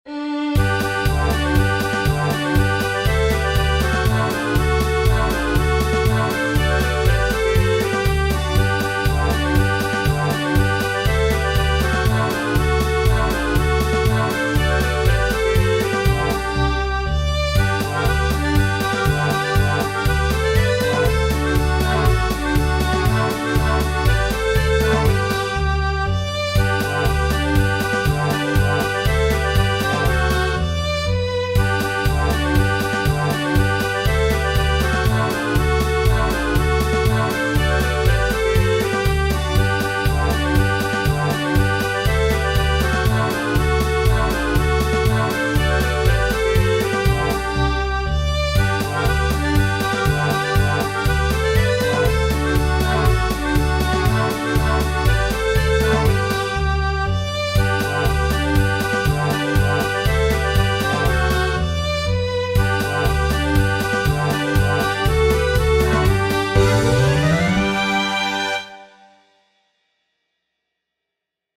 Ein schönes Lied zum Mitsingen mit Geige, B-Klarinette, Akkordeon, Bass und Schlagzeug.
A beautiful song to sing along with fiddle, clarinet, accordeon, bass and drums.